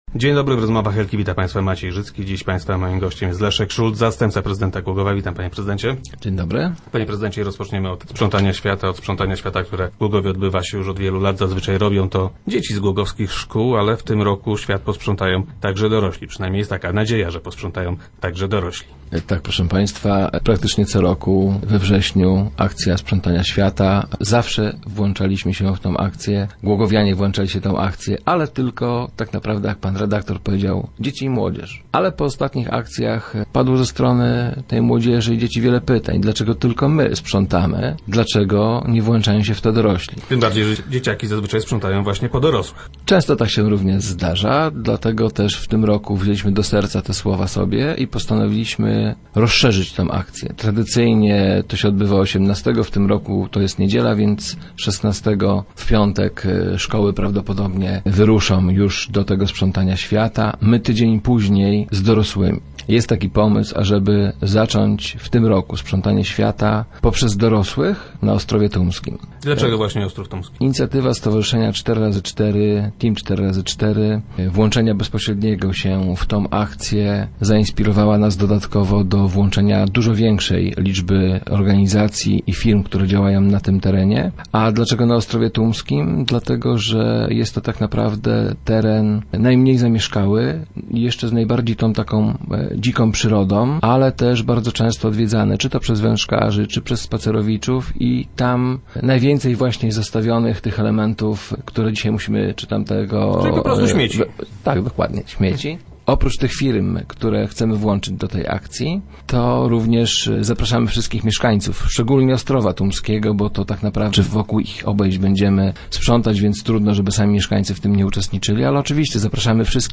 - Bardzo często są to osoby nieprzygotowane zawodowo, więc prace interwencyjne są dla nich wskazaną formą zarobkowania. Pozostawanie przez długi czas bez pracy tworzy pewnego rodzaju patologie i powoduje to, że bezrobotni tracą często chęć do życia - mówi wiceprezydent Szulc, który był gościem Rozmów Elki.